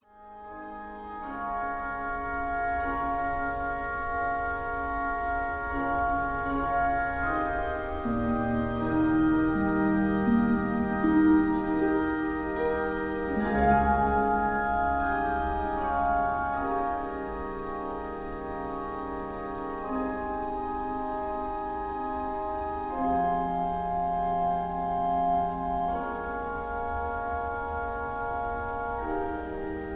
Key-Ab
mp3 backing tracks